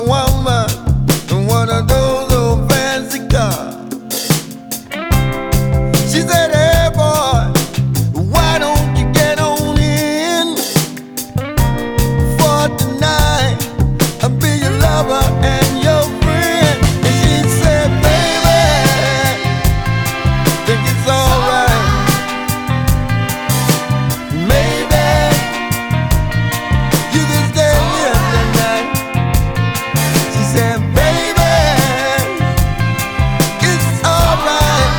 Жанр: Рок / R&B / Соул